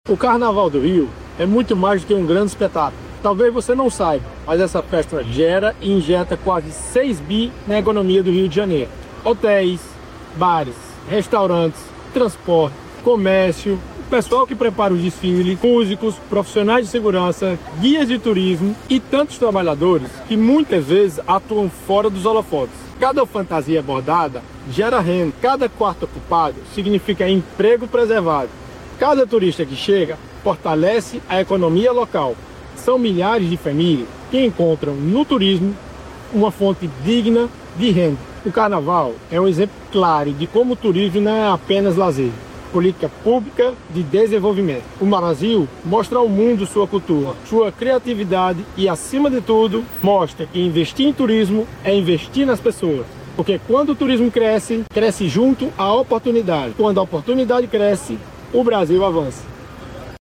O ministro do Desenvolvimento e Assistência Social, Família e Combate à Fome conversou com jornalistas na saída de uma reunião com o ministro da Fazenda, Fernando Haddad, na tarde desta quinta-feira (17), em Brasília.